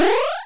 heal_a.wav